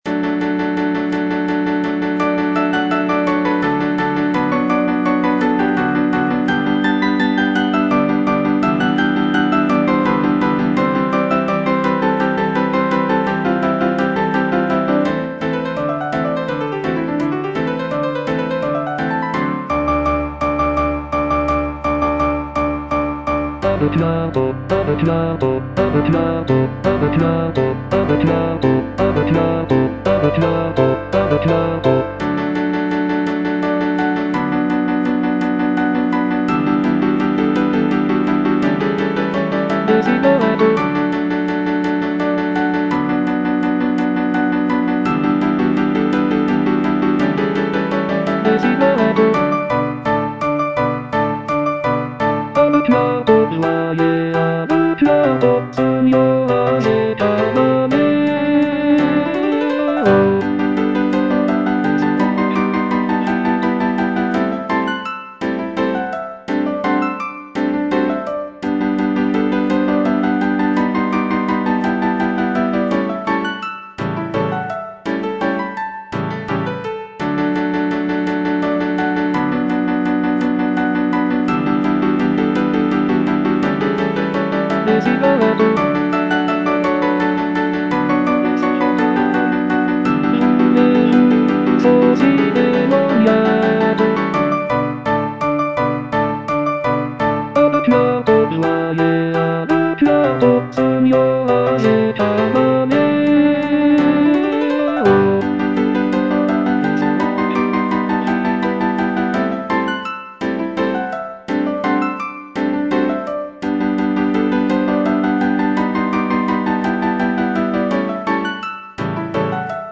G. BIZET - CHOIRS FROM "CARMEN" A deux cuartos (bass I) (Voice with metronome) Ads stop: auto-stop